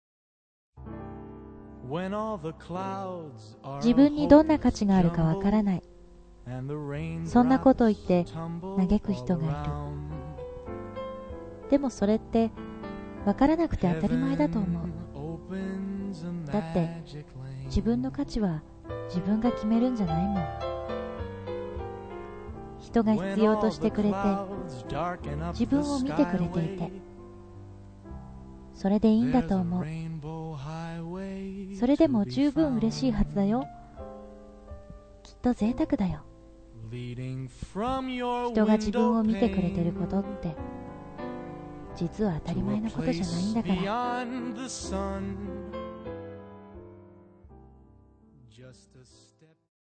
朗読
しっとりとした大人が雰囲気がムンムンですｗｗ